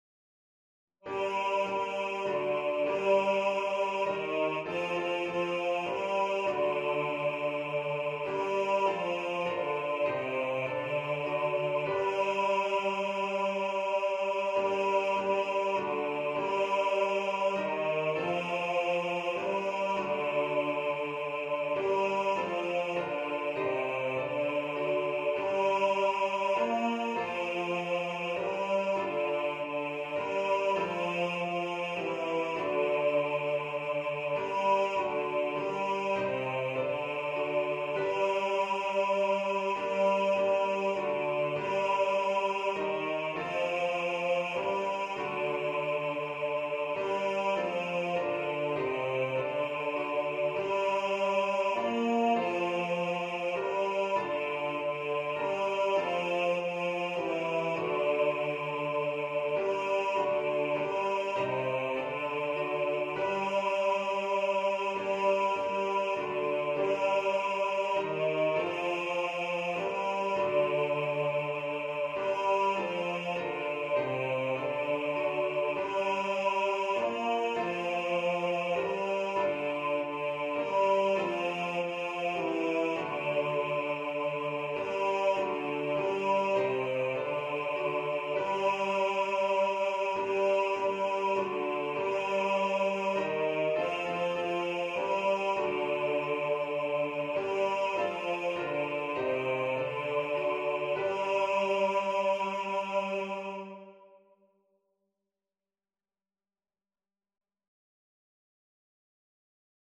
Coventry-Carol-Bass.mp3